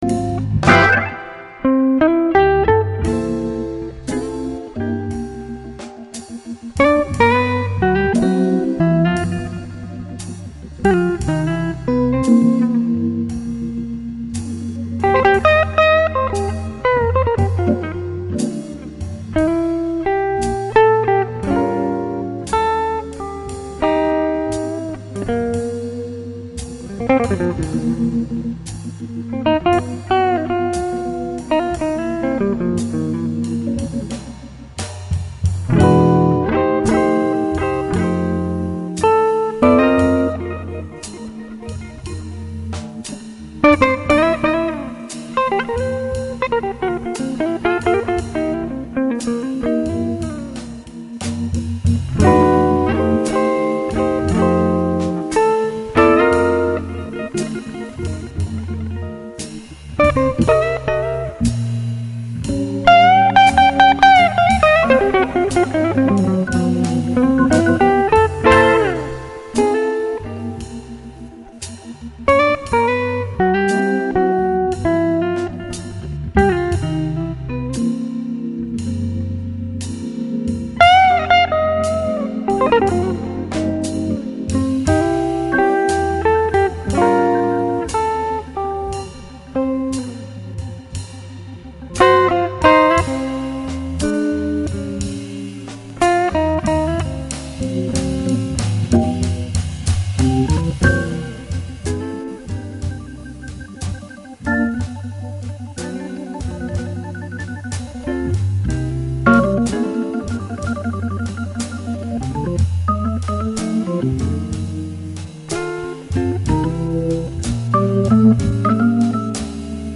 Pas trop d'idée, mais ça groove !
Mais bluesy, ça... OK.